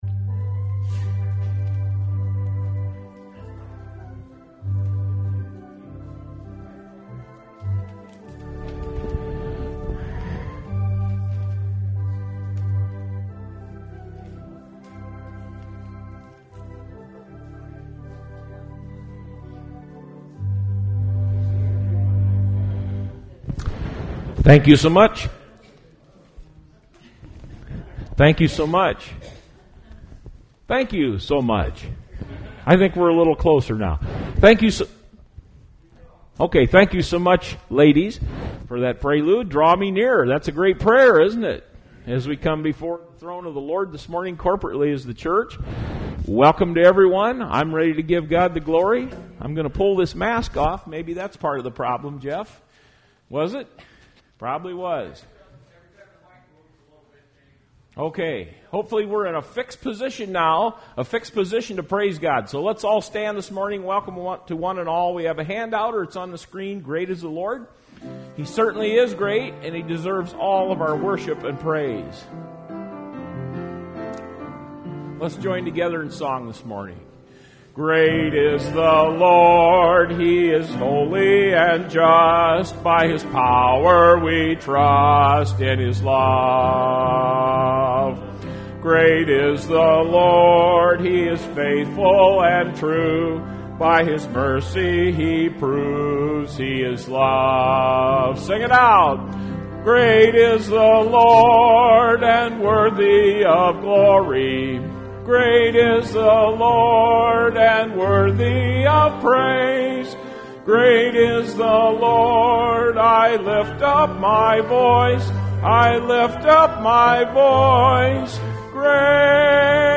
Service Type: Sunday Morning Service Topics: Christian Living , Spiritual Growth